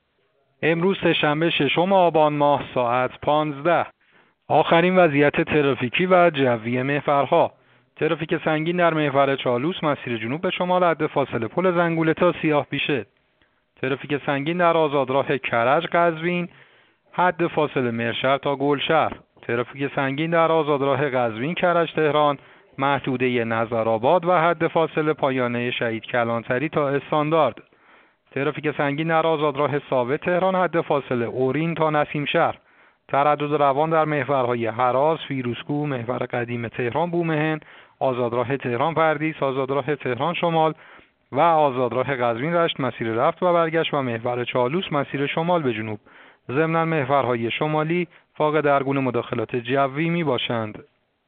گزارش رادیو اینترنتی از آخرین وضعیت ترافیکی جاده‌ها ساعت ۱۵ ششم آبان؛